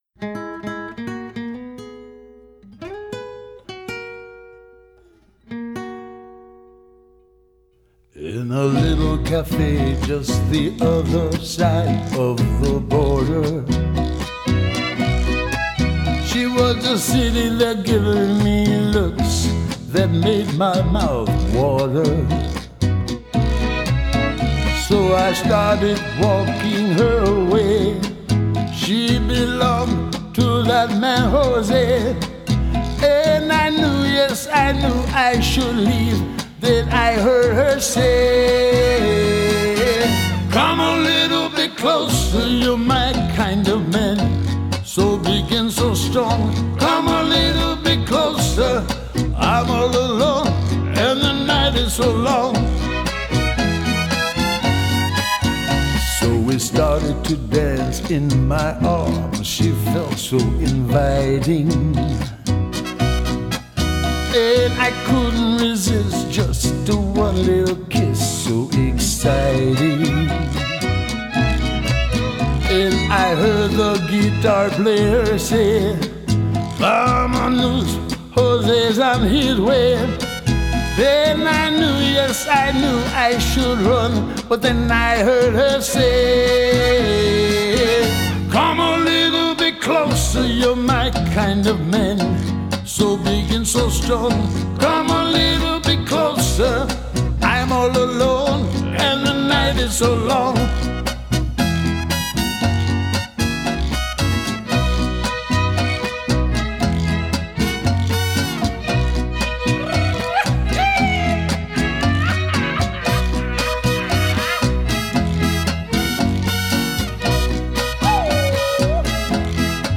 un cantante, guitarrista, compositor norteamericano
con aroma R&B y si quieren un poquito de toque puertoriqueño